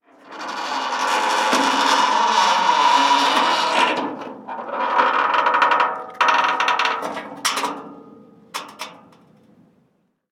Chirrido de una puerta de metal exterior
puerta
chirriar
metal
Sonidos: Hogar